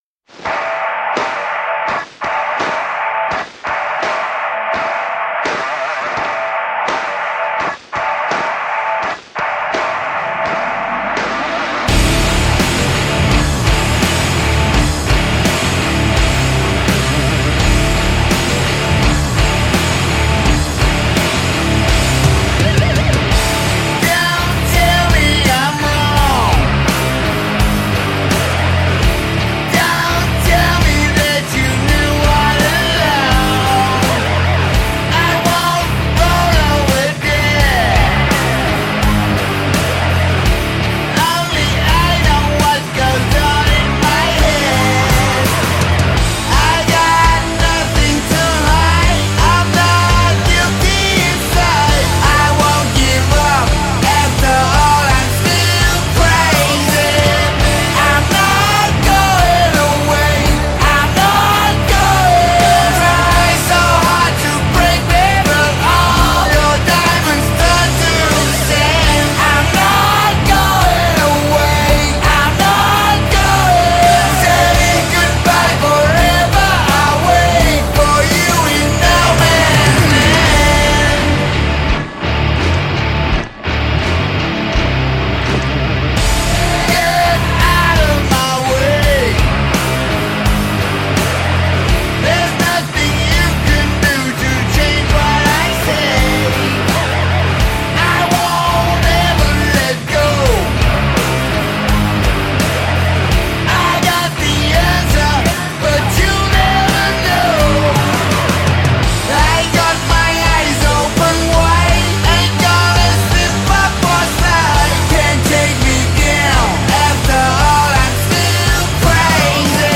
Heavy Metal, Hard Rock